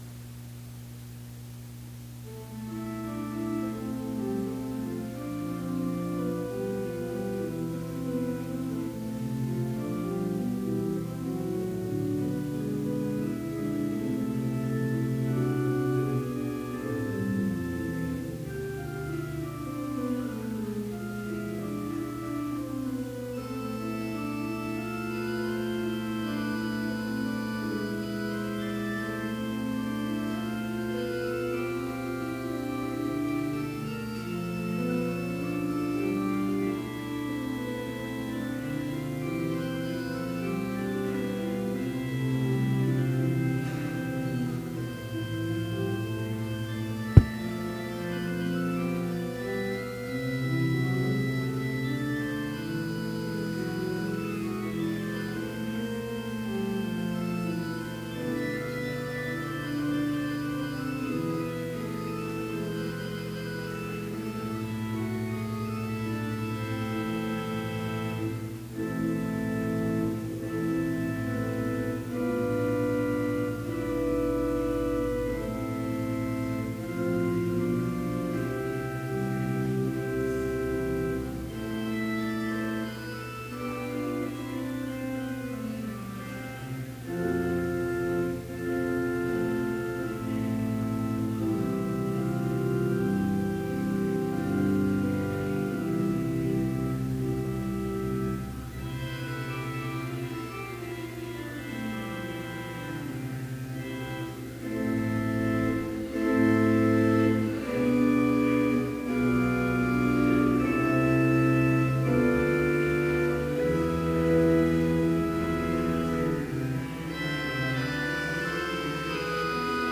Complete service audio for Chapel - March 19, 2018